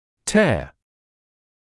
[teə][тэа]разрыв, разрывание; разрывать; [tɪə] слеза